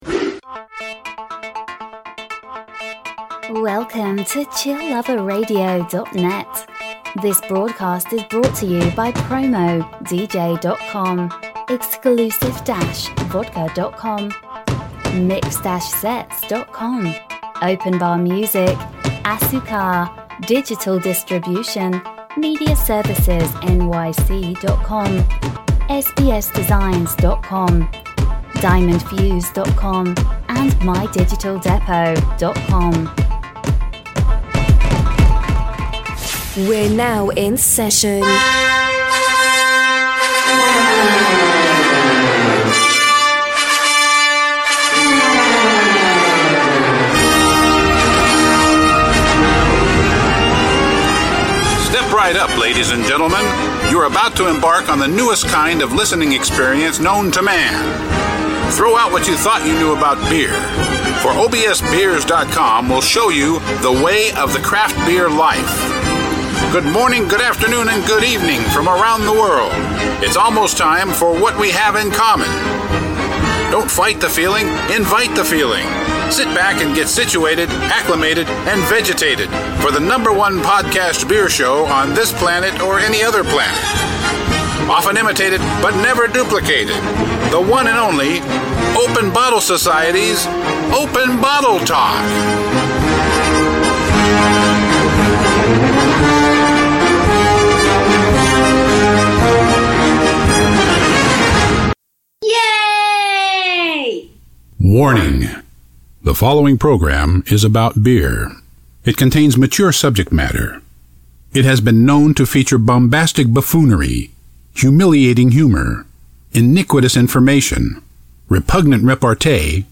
Styles: Beer Talk, Beer News, Beer, Craft Beers, Talk Show, Comedy